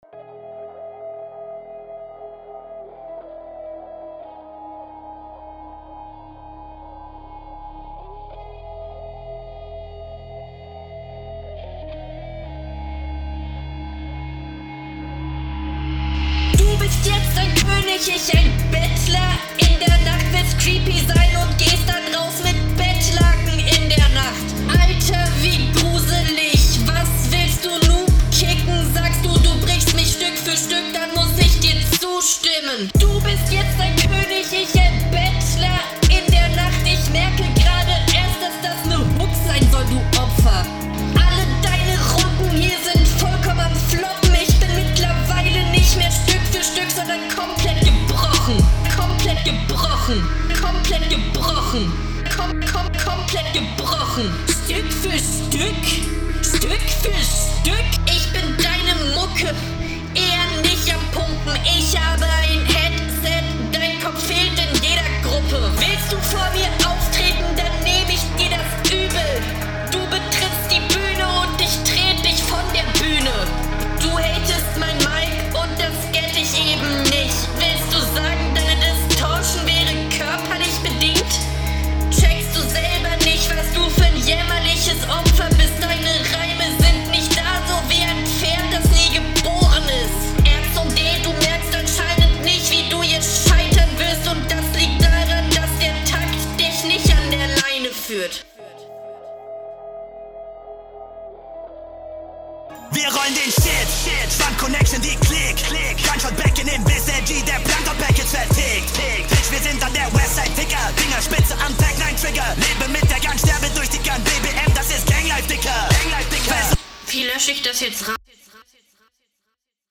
zwei flowpunkte weil man versteht immerhin etwas (ich weiß nicht ob das besser ist)
guter... beat ich mag den beat sehr also der beatpick ist schon cool produzent hat …
Scheppert!